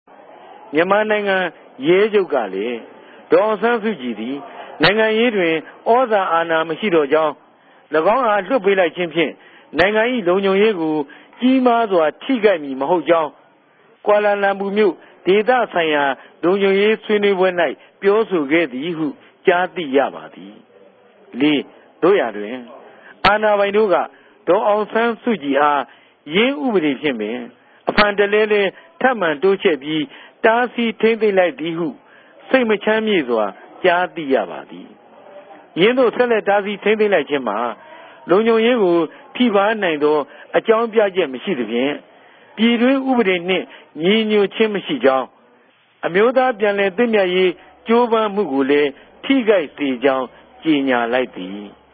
ဖတ်ူပသြားတာကိုလဲ နားထောငိံိုင်ပၝတယ်။